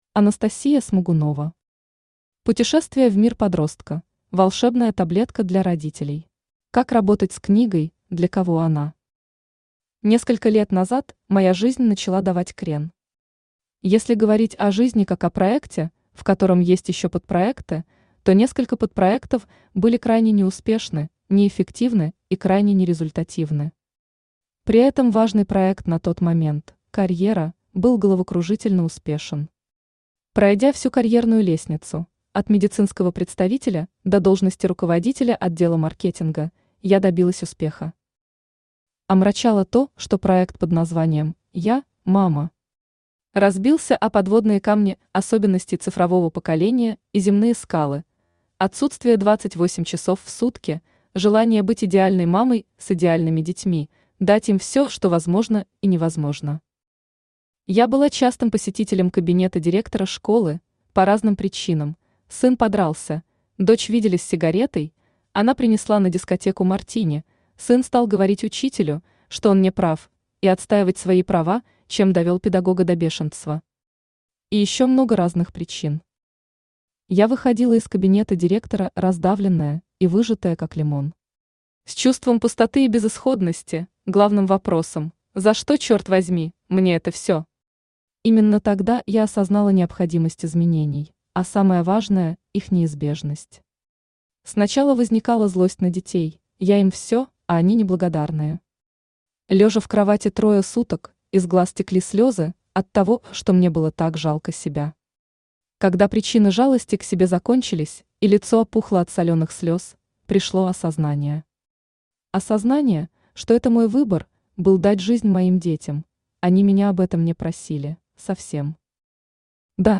Aудиокнига Путешествие в мир подростка:волшебная таблетка для родителей Автор Анастасия Александровна Смогунова Читает аудиокнигу Авточтец ЛитРес.